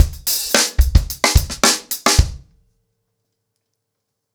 TupidCow-110BPM.21.wav